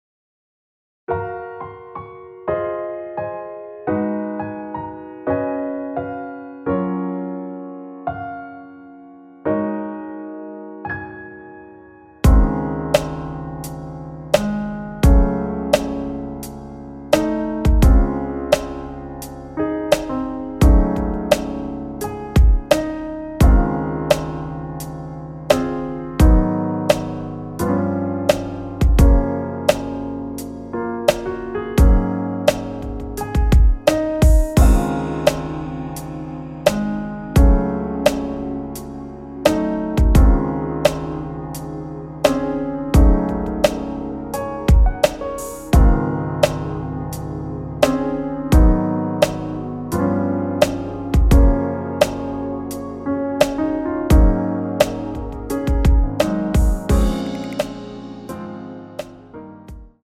원키에서(-2)내린 MR입니다.
Db
앞부분30초, 뒷부분30초씩 편집해서 올려 드리고 있습니다.
중간에 음이 끈어지고 다시 나오는 이유는